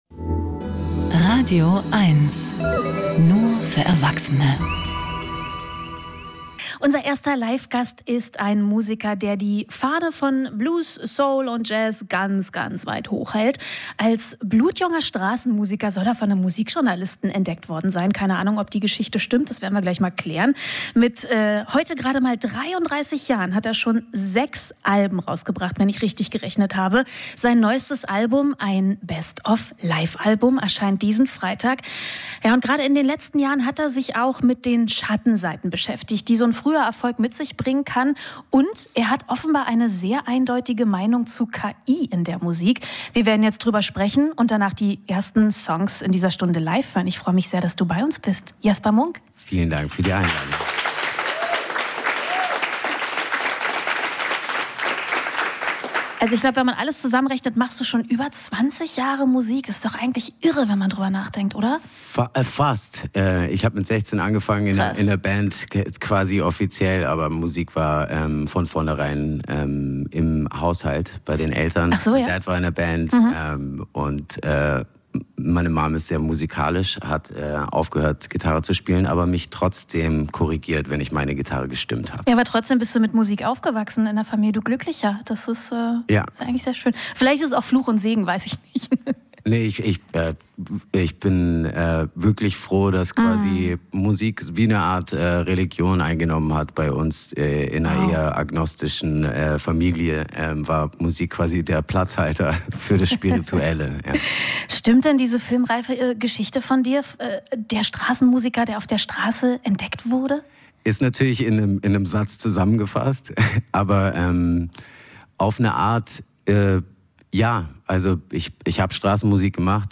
Gespräche